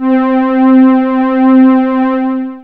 SMOOV STRS.wav